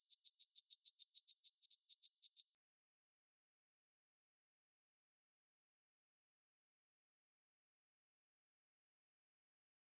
Ojos Negros shieldback
10 s of calling song and waveform. Baja California, Mexico; 25.0°C. R88-135.